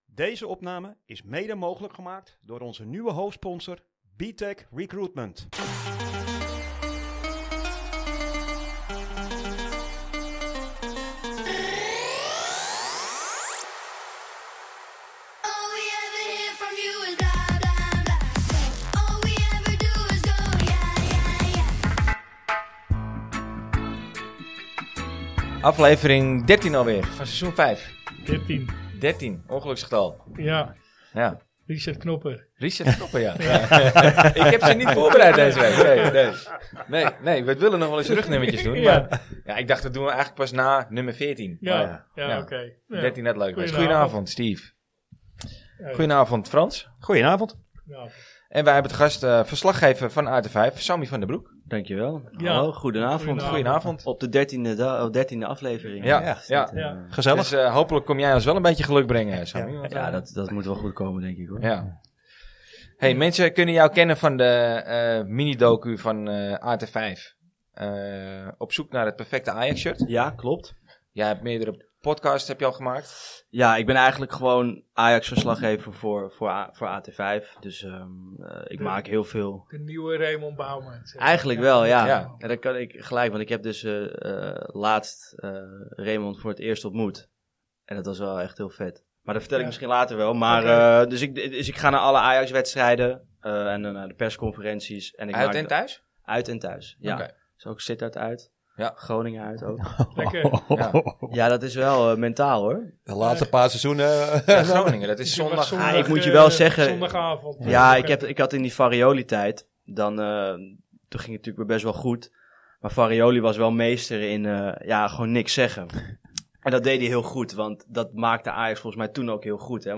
De Ajax podcast voor supporters, door supporters en met supporters! Amsterdamse jongens die de wedstrijd analyseren op een kritische manier, maar wel met de nodige humor, regelmatig interessante gasten vanuit de Ajax supporterswereld of vanuit de voetbalwereld zelf.